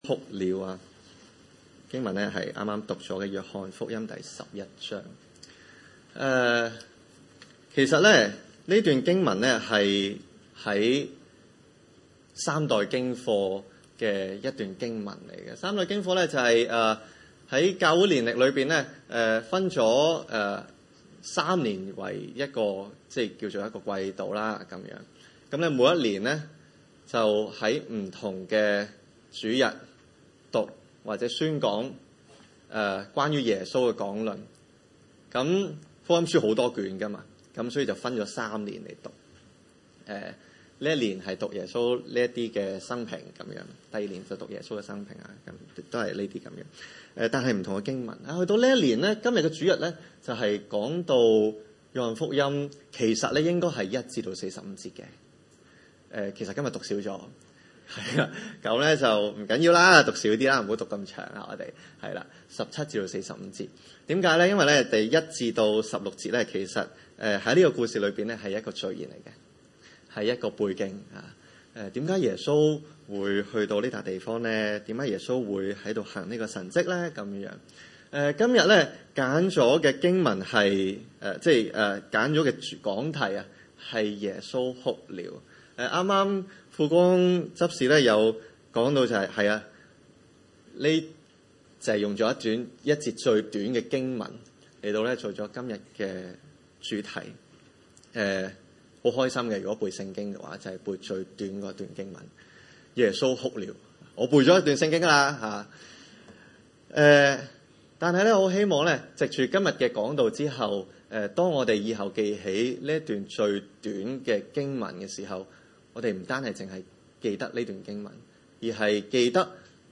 經文: 約翰福音十一:17-45 崇拜類別: 主日午堂崇拜 17.